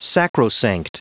Prononciation du mot sacrosanct en anglais (fichier audio)